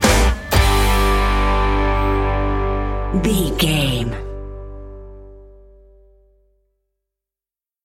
Ionian/Major
electric guitar
drums
bass guitar
Pop Country
country rock
bluegrass
blues
happy
uplifting
powerful
driving
high energy